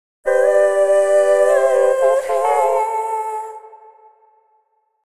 各エフェクトの Mix と、Wet音だけを抽出したものです。
ボーカル・ホール系のプリセットを、ほぼそのまま AUX に置き、リターン量が同じになるように設定。